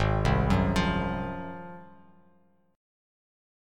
G#13 Chord
Listen to G#13 strummed